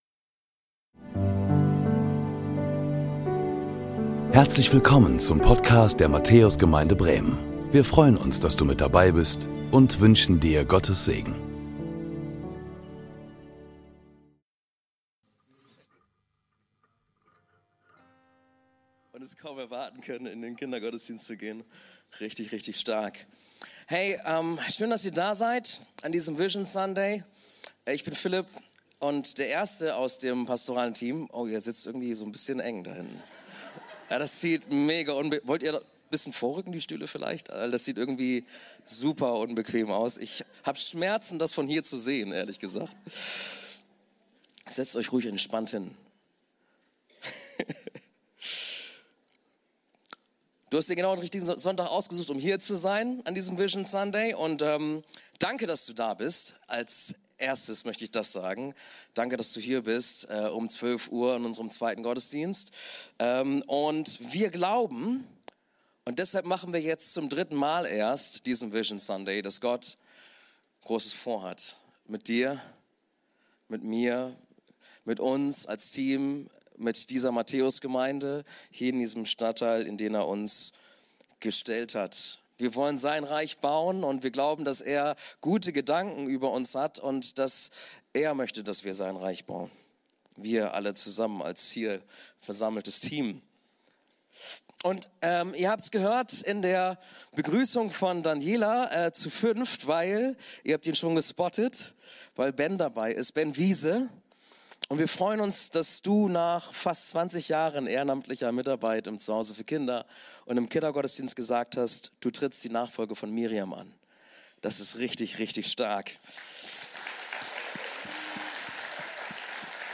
Herzlich Willkommen zu unserem 2. Gottesdienst um 12 Uhr. Heute ist unser Vision-Sunday, wo unser pastorales Team euch unsere mittelfristige Gemeindevision vorstellen wird.
Predigten der Matthäus Gemeinde Bremen